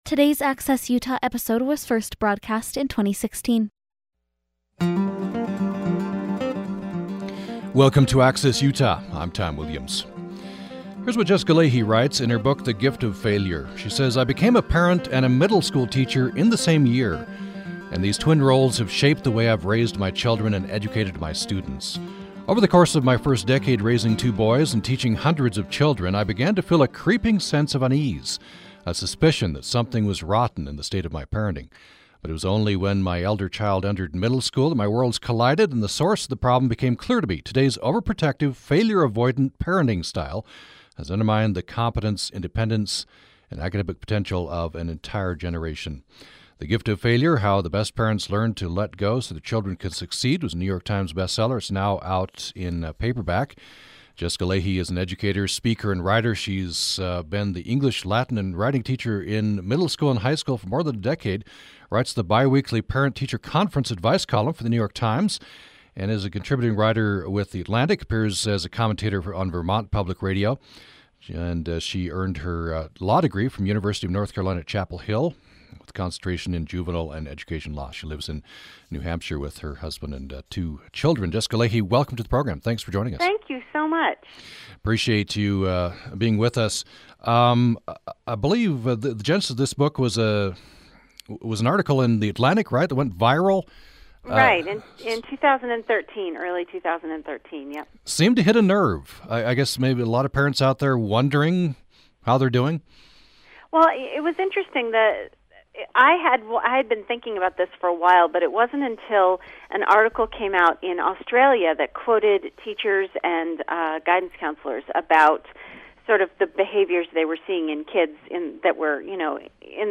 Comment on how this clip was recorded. Access Utah is UPR's original program focusing on the things that matter to Utah. The hour-long show airs live Monday-Thursday at 9:00 a.m. Access Utah covers everything from pets to politics in a range of formats from in-depth interviews to call-in shows.